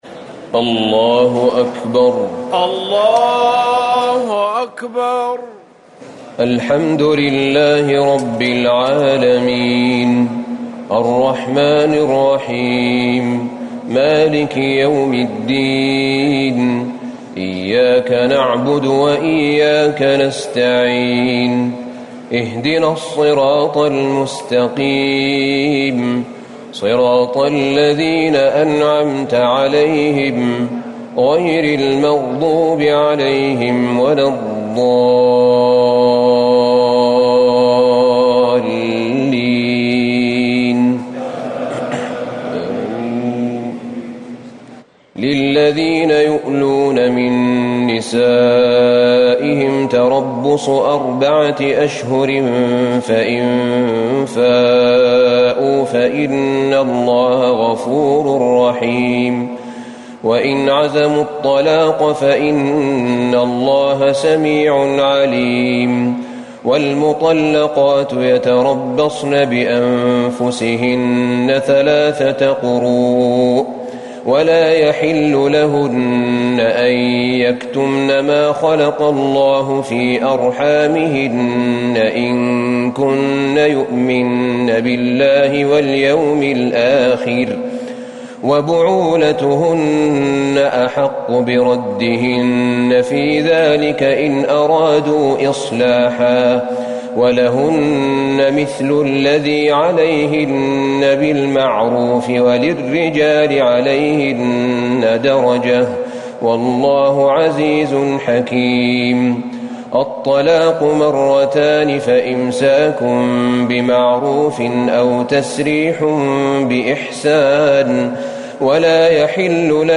ليلة ٢ رمضان ١٤٤٠هـ من سورة البقرة ٢٢٦-٢٧١ > تراويح الحرم النبوي عام 1440 🕌 > التراويح - تلاوات الحرمين